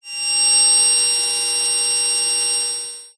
Звук внезапного воспоминания у пациента под гипнозом